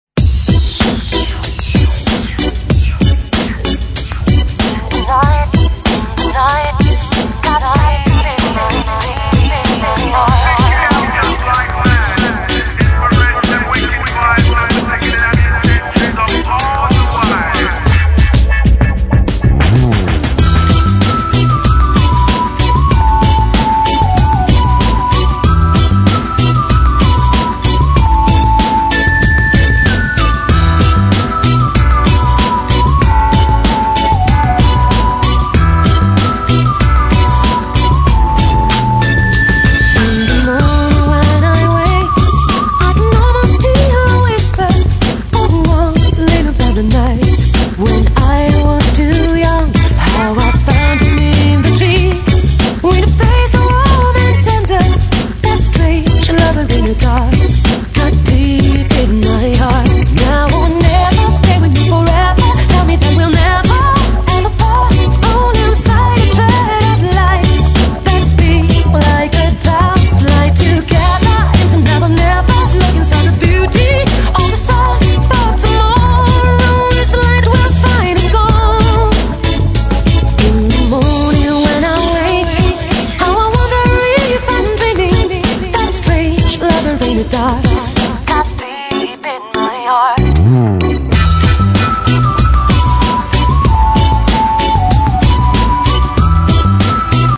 Pop Dance Edit
※試聴は音質を落しています。